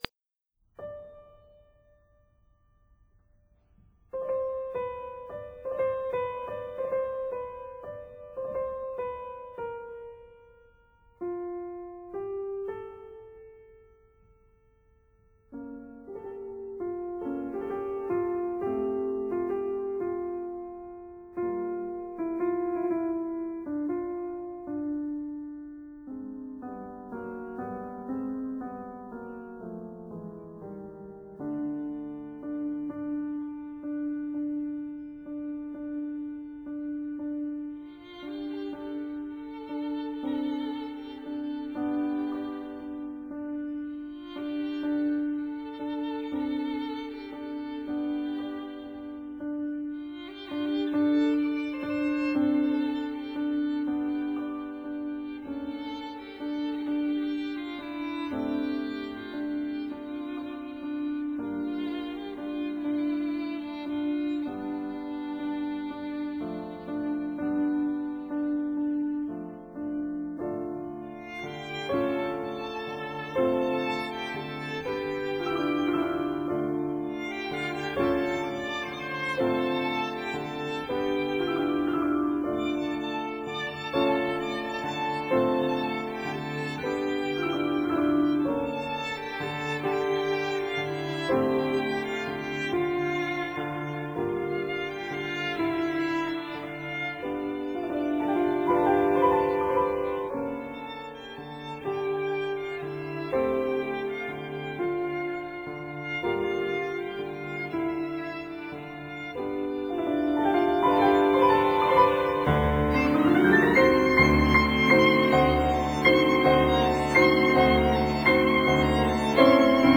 •(02) Trio for Clarinet, Violin and Piano
piano
violin
clarinet
cello